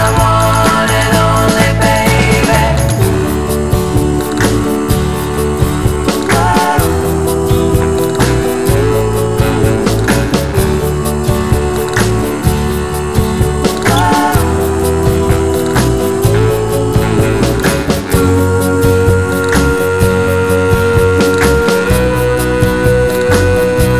One Semitone Down Christmas 2:46 Buy £1.50